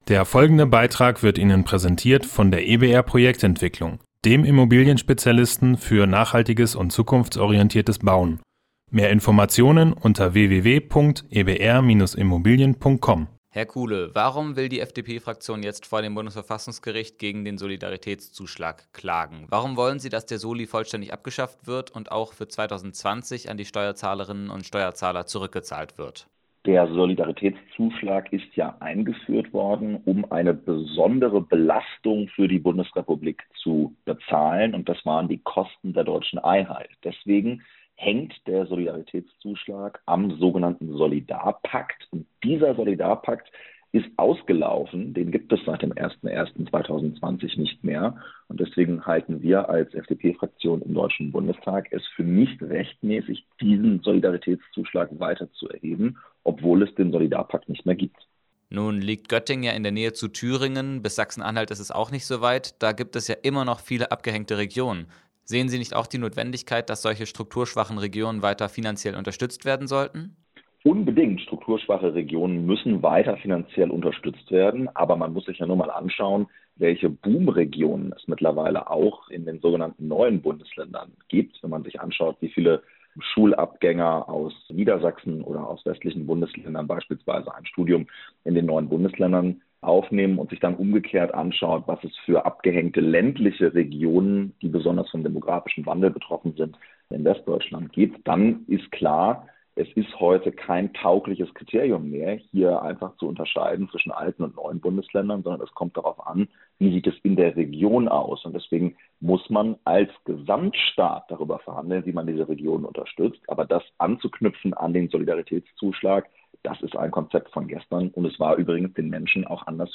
Beiträge > FDP-Fraktion klagt gegen Solidaritätszuschlag: Interview mit dem Göttinger MdB - StadtRadio Göttingen